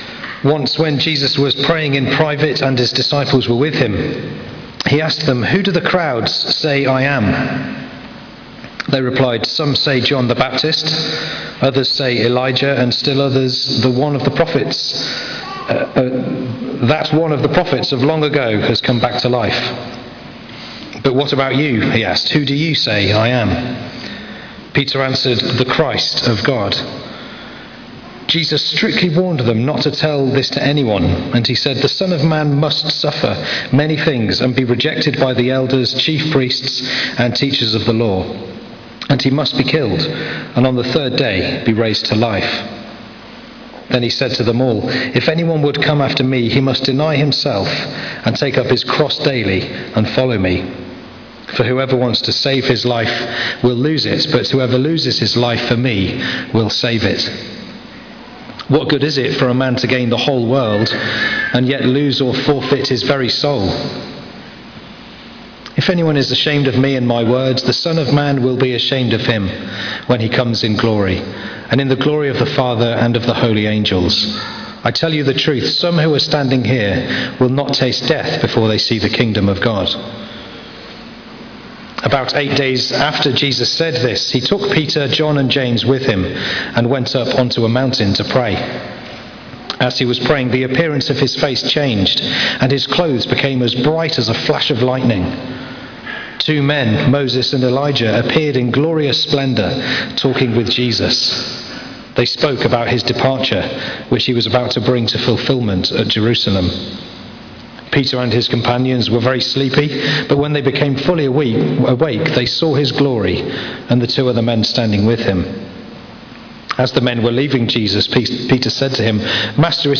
Learning From Luke Passage: Luke 9:18-36 Service Type: Sunday 11:00am